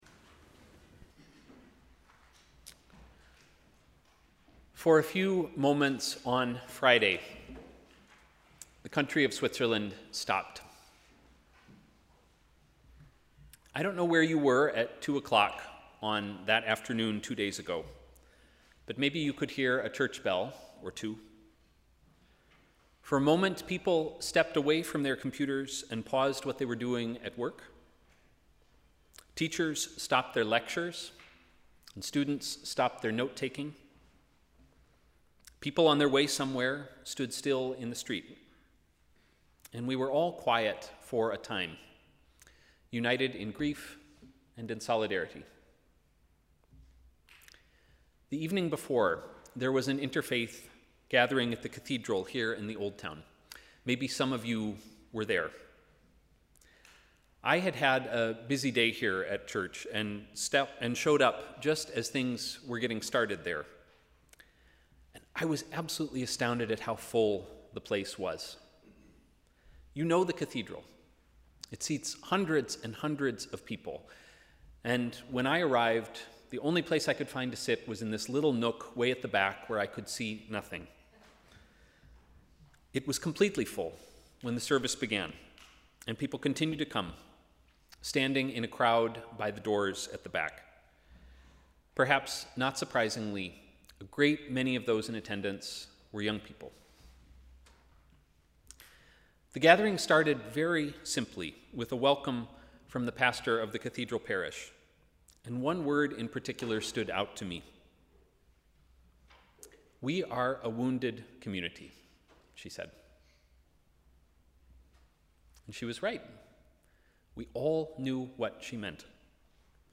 Sermon: ‘Here with us’